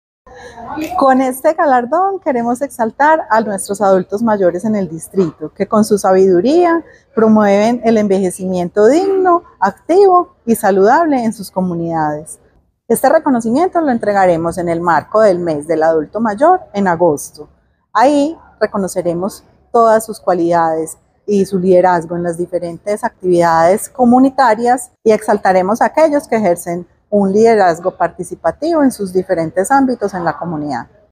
Palabras de Clara Vélez, subsecretaria de Inclusión Social y Familia